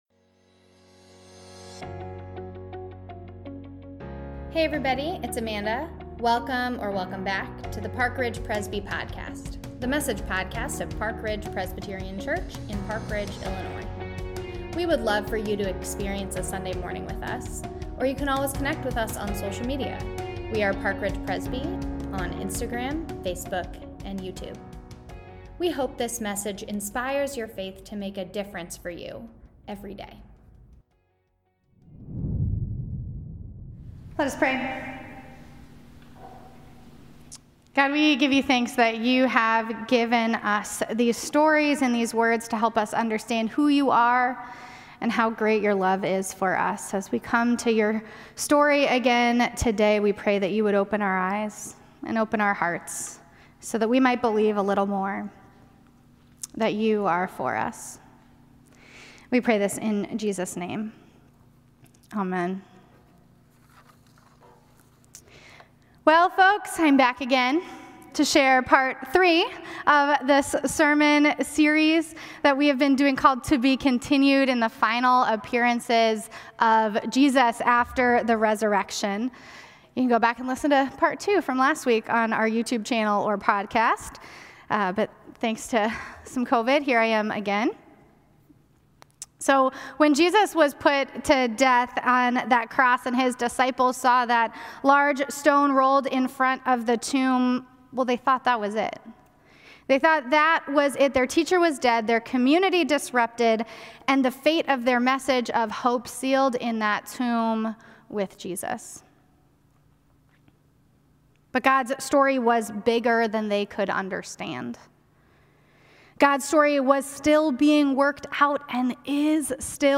Thank you for worshiping with us today!
Mothers-Day-Message.mp3